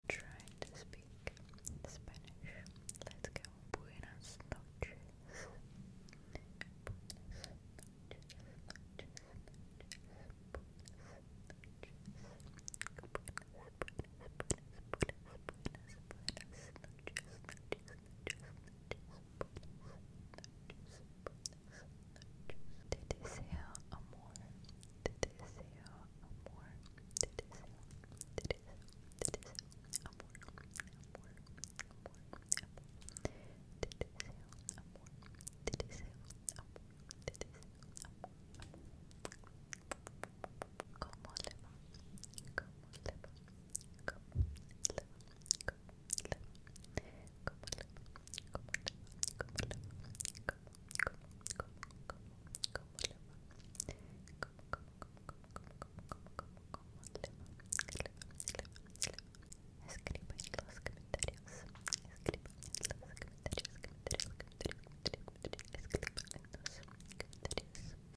ASMR trying to speak Spanish sound effects free download